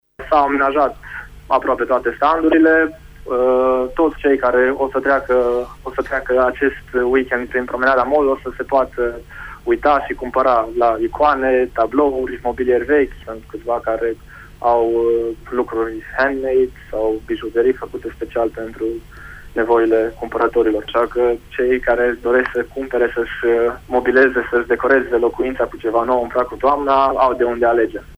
a vorbit la emisiunea “Pulsul zilei” de la Radio Tg.Mureș, despre oferta ediției de toamnă: